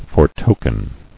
[fore·to·ken]